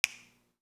DelaySnap.wav